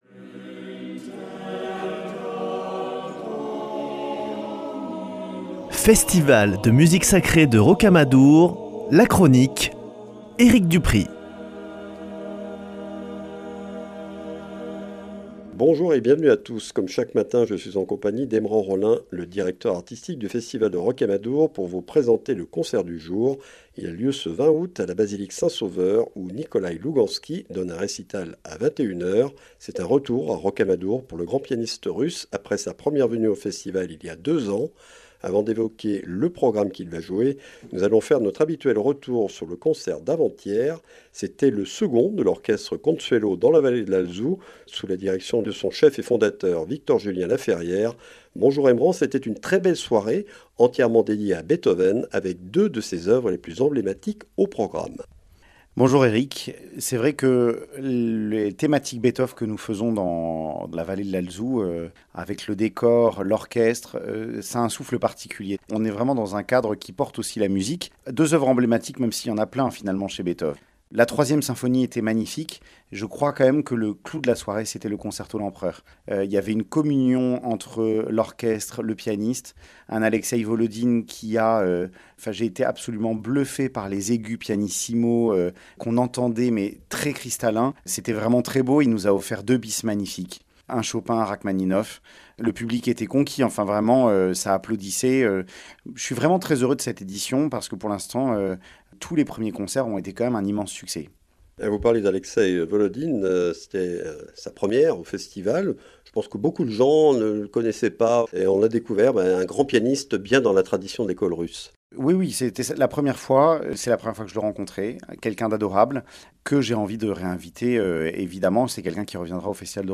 Chronique Rocamadour